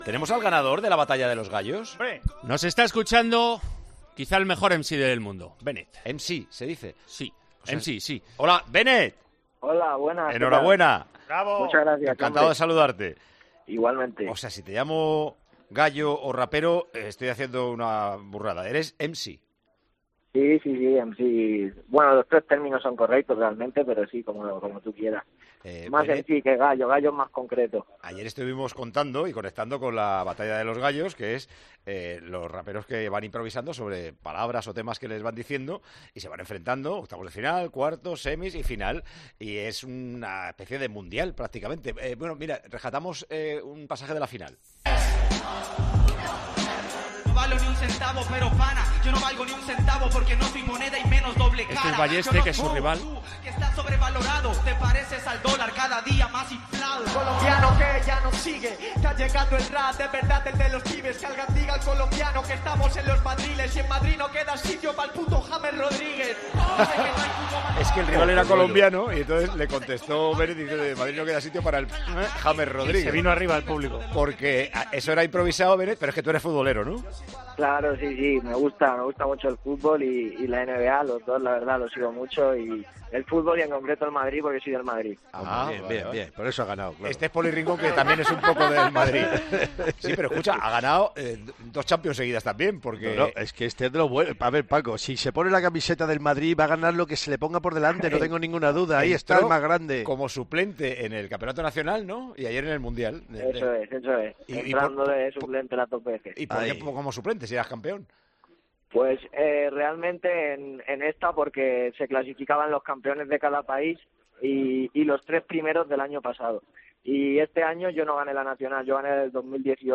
Con Paco González, Manolo Lama y Juanma Castaño